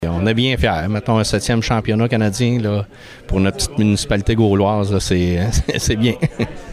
La fierté du maire, Laurent Marcotte, était palpable lors de la conférence de presse de lundi alors qu’on dévoilait les détails entourant la compétition.